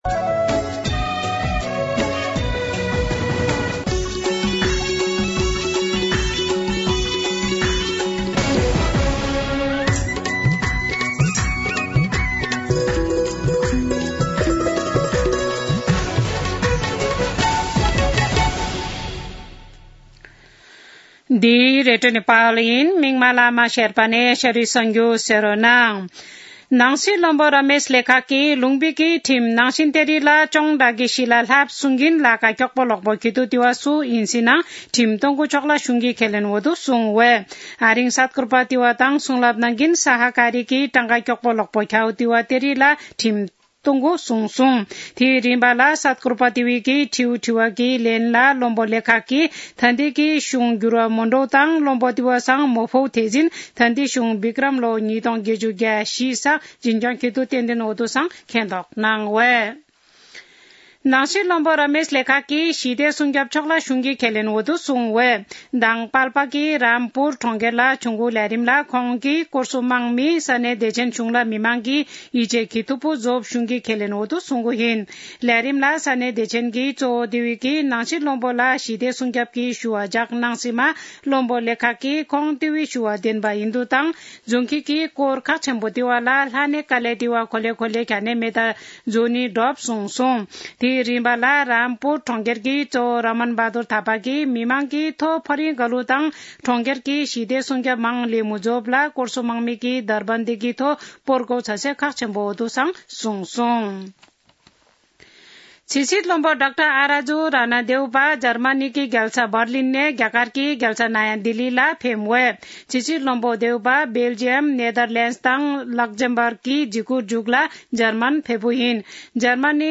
शेर्पा भाषाको समाचार : ५ पुष , २०८१
Serpa-News-09-4.mp3